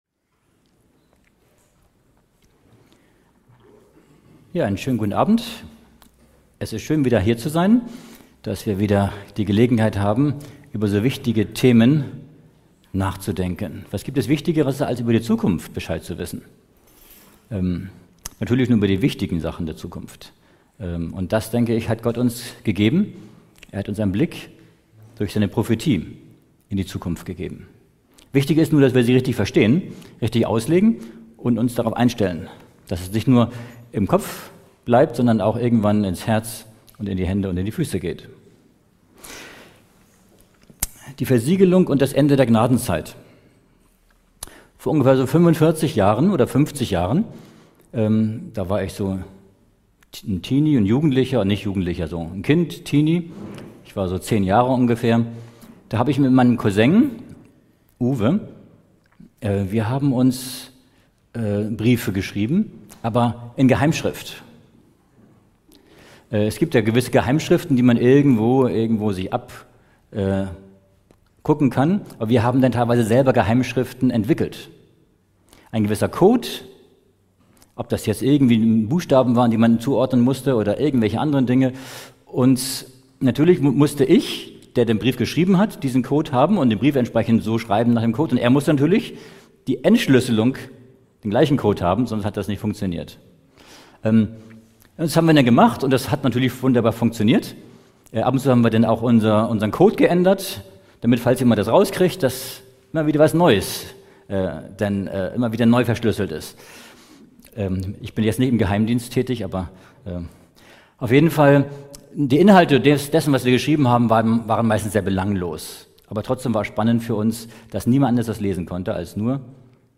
Ein faszinierender Vortrag über die Prophetie und das Zeitgeschehen, der tief in die Themen der Endzeitversiegelung und der Gnadenzeit eintaucht. Er beleuchtet, wie diese Konzepte mit den biblischen Schriften und dem Charakter Gottes verbunden sind.